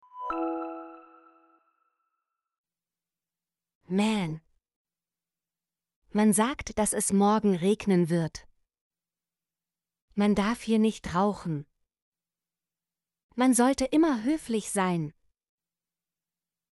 man - Example Sentences & Pronunciation, German Frequency List